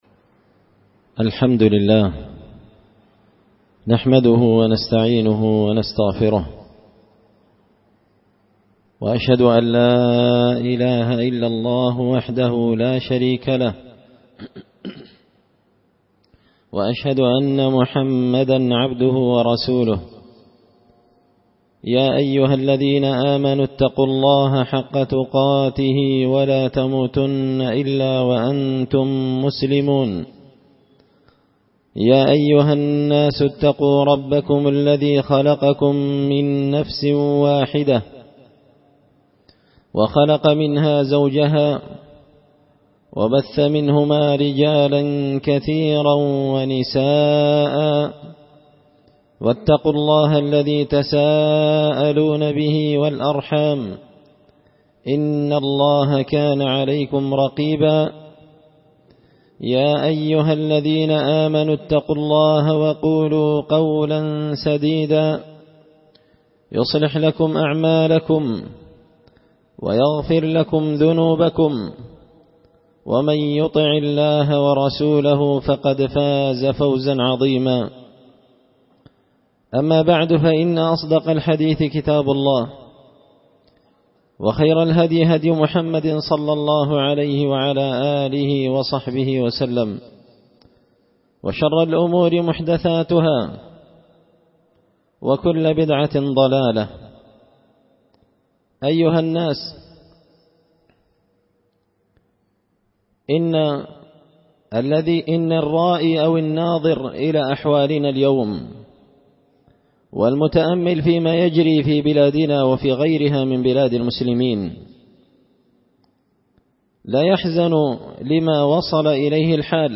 خطبة جمعة بعنوان – النصح المختار لعموم الناس والتجار
دار الحديث بمسجد الفرقان ـ قشن ـ المهرة ـ اليمن